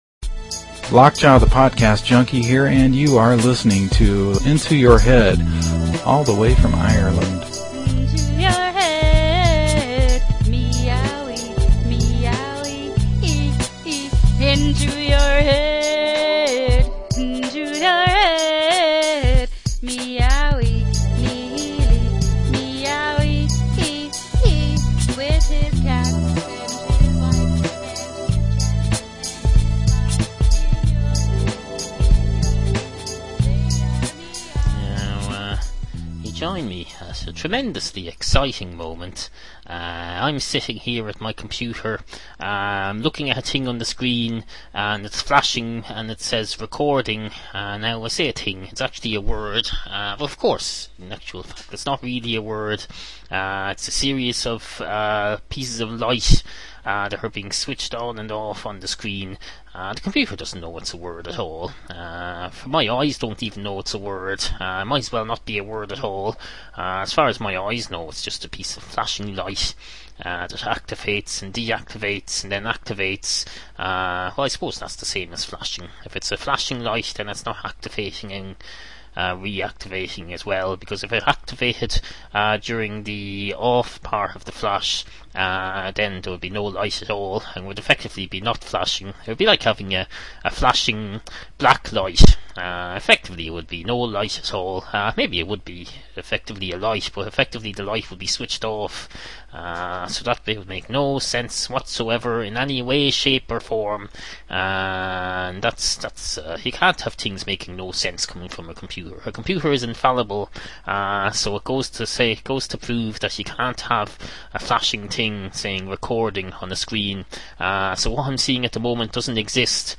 We’re back with a brand spanking new episode, packed to the brim with meaningless monologues and the occasional musical number!